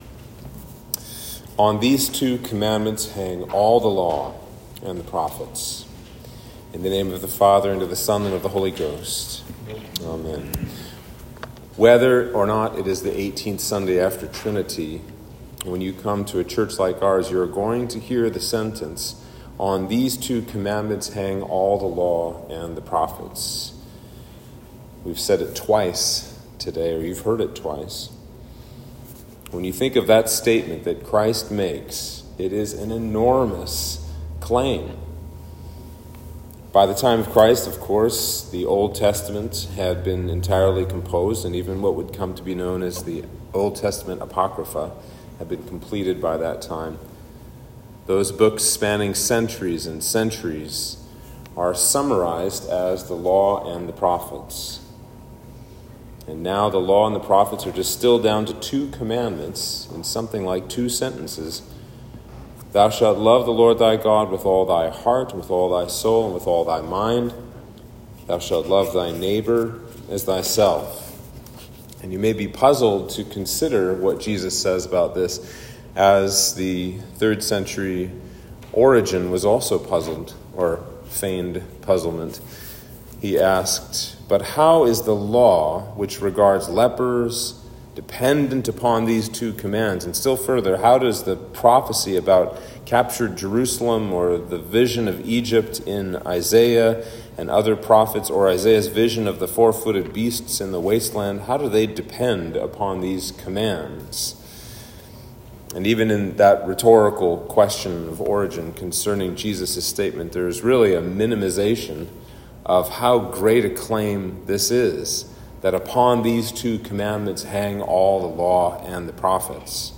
Sermon for Trinity 18